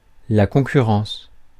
Ääntäminen
IPA: /kɔ̃.ky.ʁɑ̃s/